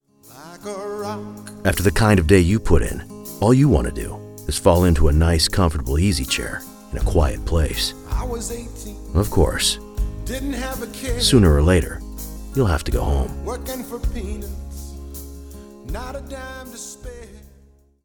Male
Television Spots
Words that describe my voice are conversational, trustworthy, authoritative.